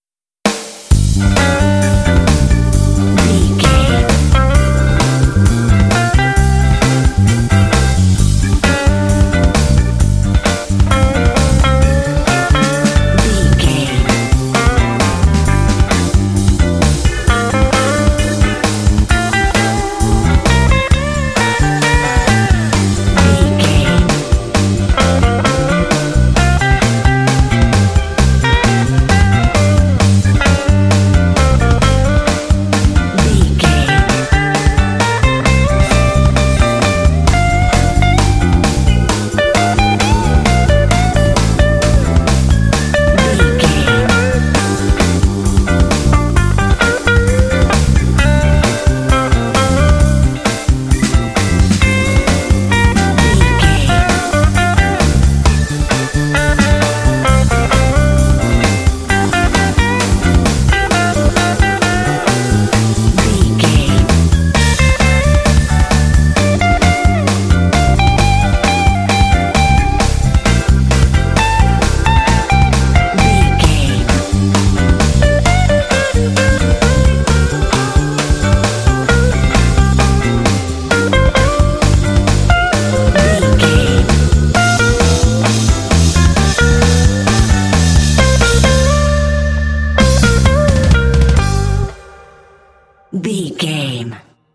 This bluesy track is full of urban energy.
Aeolian/Minor
funky
groovy
energetic
driving
electric guitar
electric organ
bass guitar
drums
blues
jazz